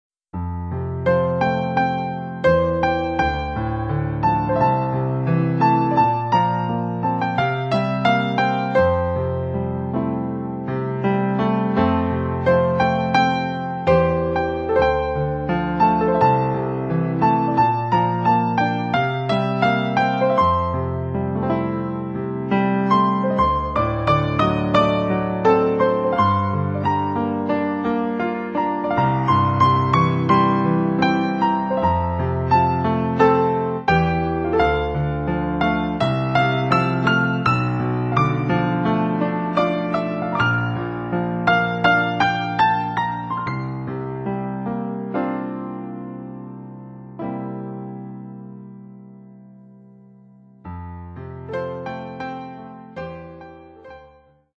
ピアノソロ版